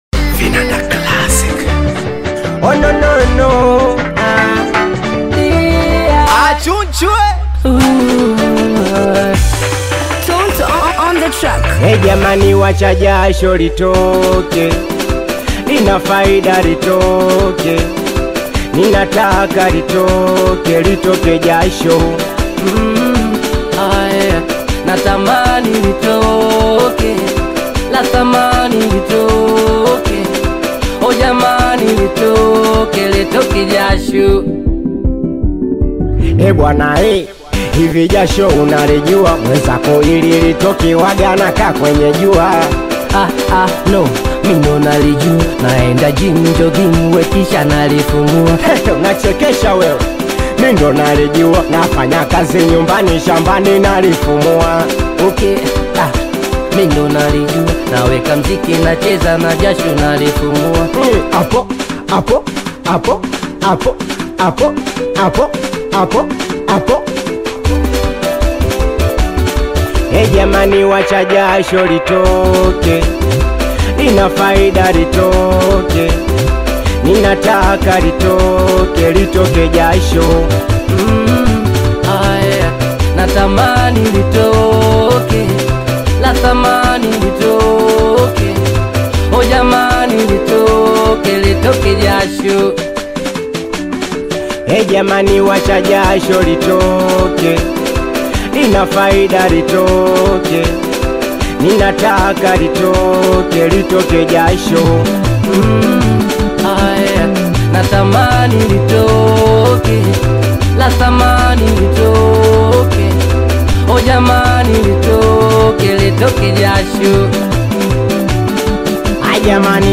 If you’re a fan of singeli music
African Music